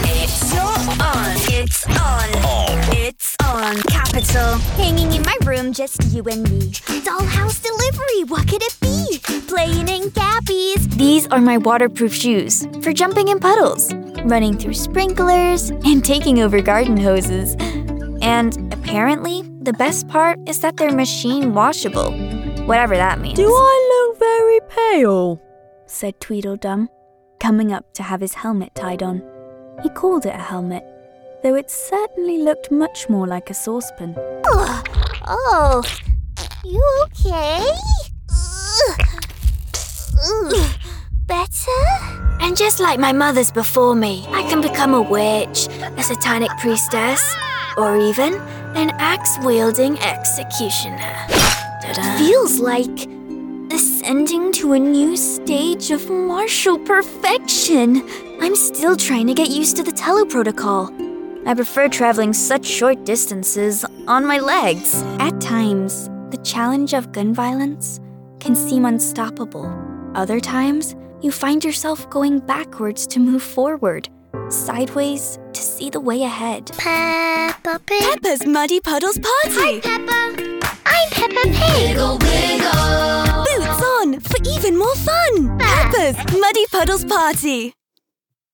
Female
Commercial reel - Spanish.mp3